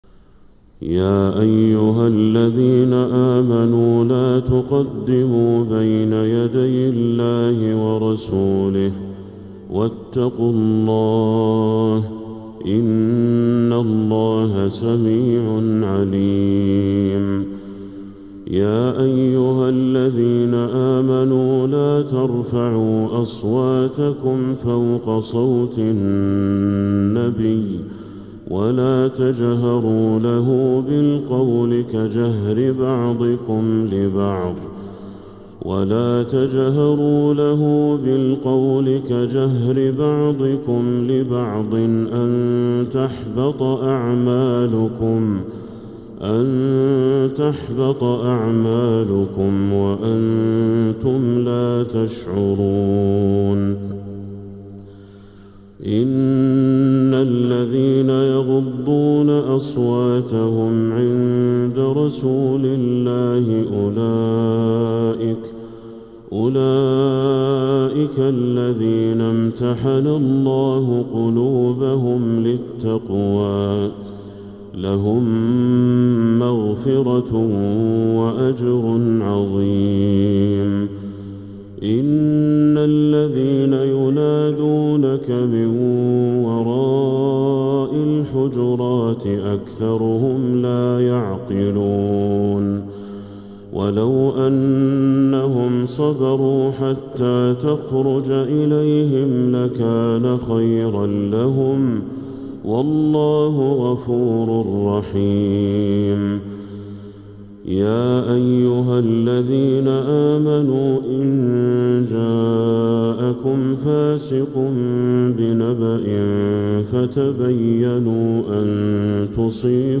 سورة الحجرات كاملة للشيخ بدر التركي > السور المكتملة للشيخ بدر التركي من الحرم المكي 🕋 > السور المكتملة 🕋 > المزيد - تلاوات الحرمين